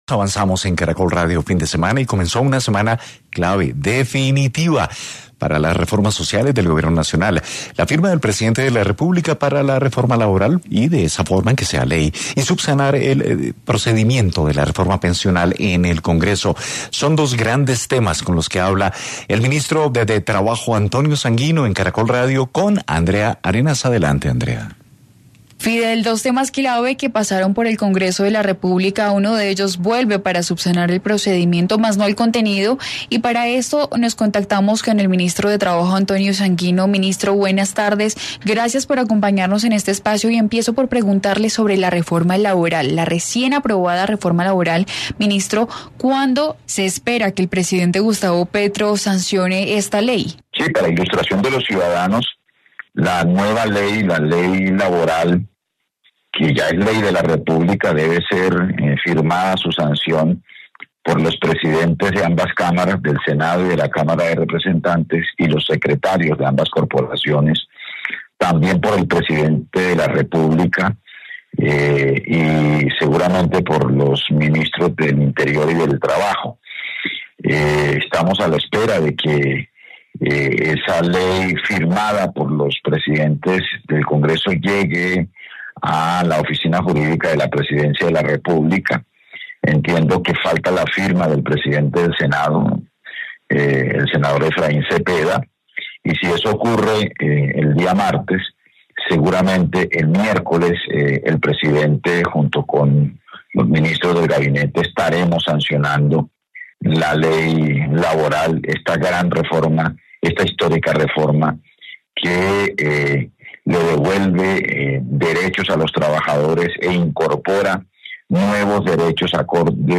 En diálogo con Caracol Radio, el Ministro de Trabajo Antonio Sanguino, se refirió al futuro de ambos proyectos, la convocatoria a las sesiones extraordinarias del Congreso de la República y la movilización popular como seguro de vida de las iniciativas promovidas por el gobierno.